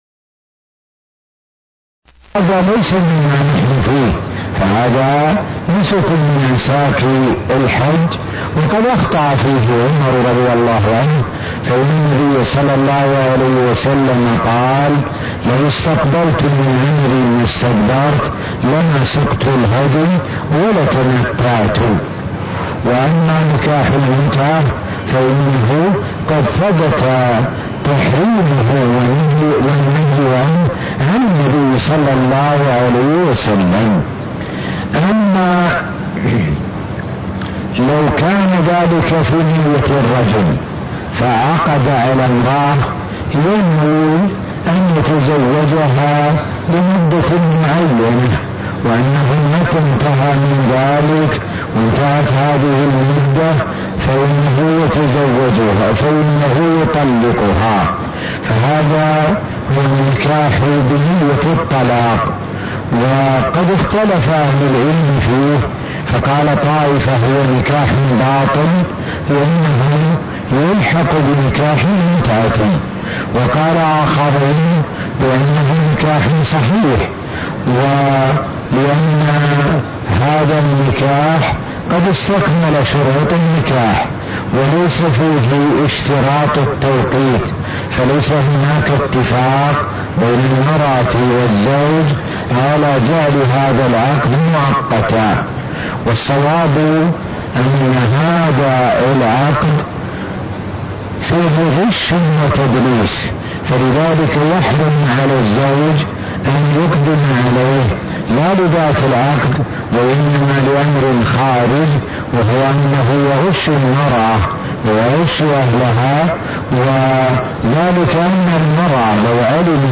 تاريخ النشر ١٩ رمضان ١٤٣١ هـ المكان: المسجد النبوي الشيخ: معالي الشيخ د. سعد بن ناصر الشثري معالي الشيخ د. سعد بن ناصر الشثري كتاب النكاح – فصل في نكاح المتعة (0002)